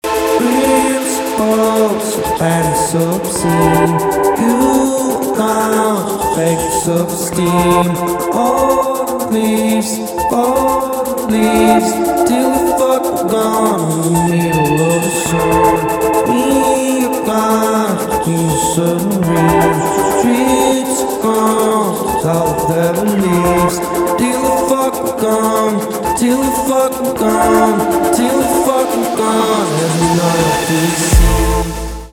электроника
битовые , басы
грустные